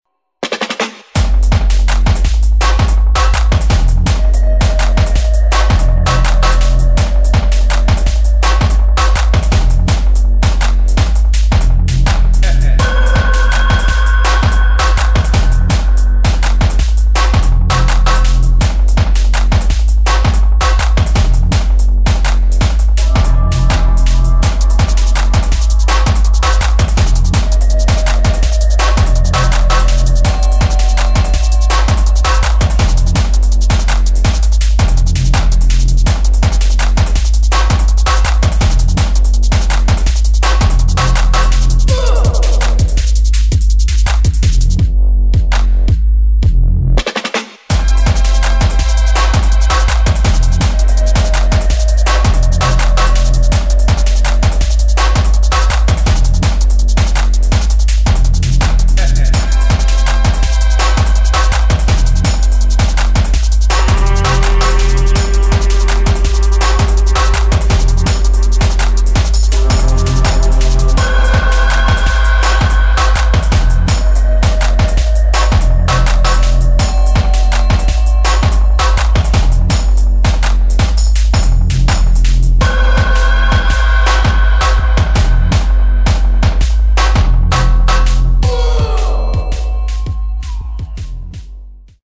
[ DUBSTEP / DRUM'N'BASS ]